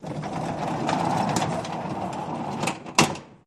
Doors-Wood
Barn Door, Slide Open